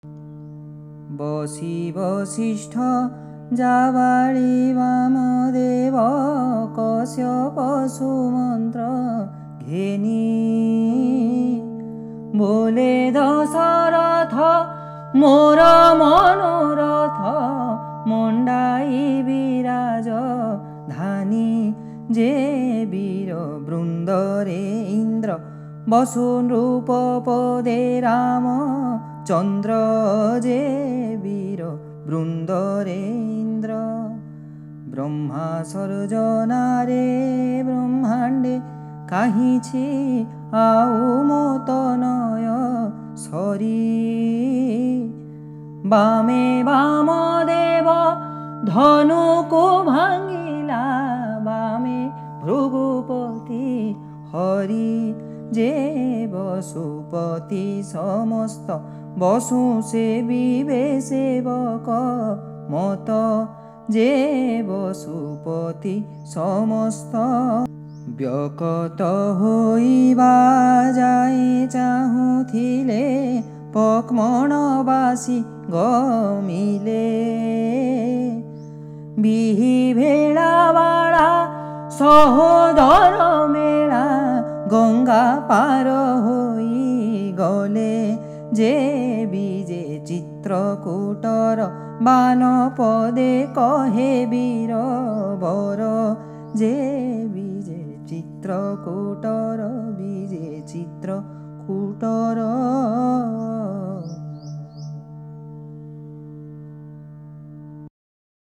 17세기 오디아 시인 우펜드라 반자의 '바이데히샤 빌라사'에서 17번째 장을 부르는 오디시 공연 예술